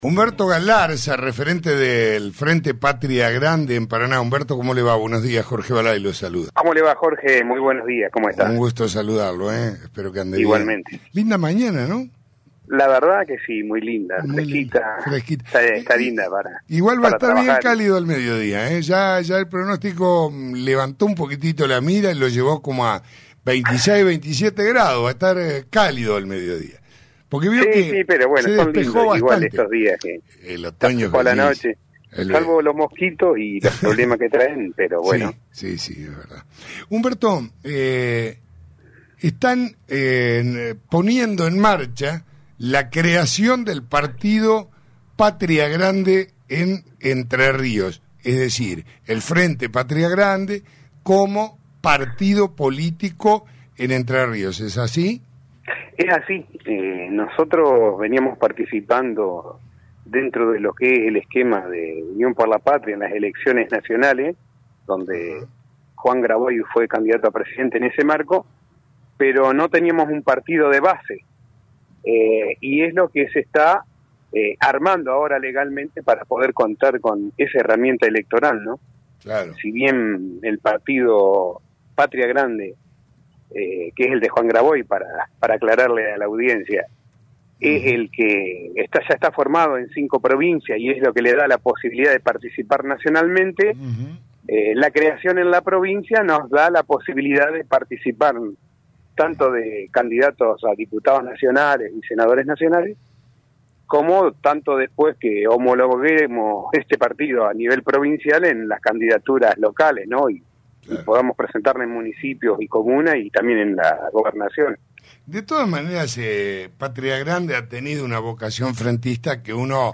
se transmite por Radio Costa Paraná (88.1)